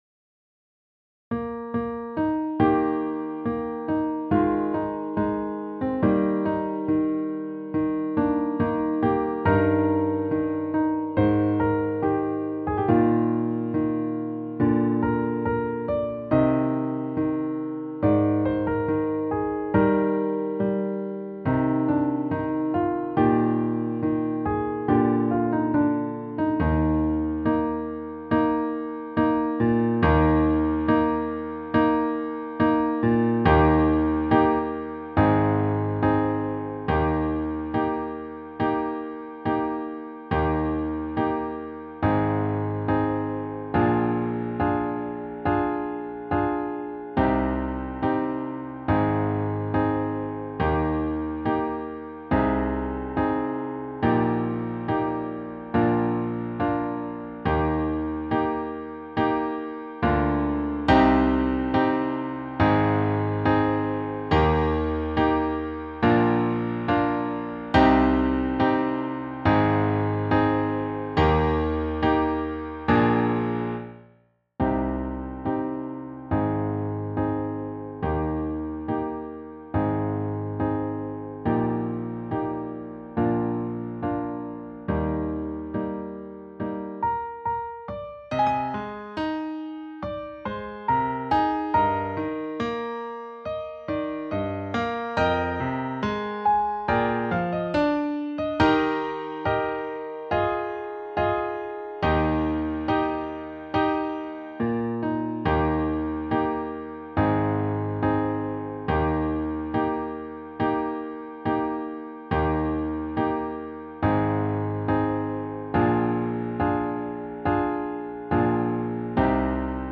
Trumpet Cover